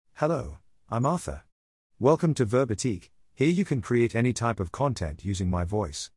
Arthur — Male British English AI voice
Arthur is a male AI voice for British English.
Voice sample
Listen to Arthur's male British English voice.
Arthur delivers clear pronunciation with authentic British English intonation, making your content sound professionally produced.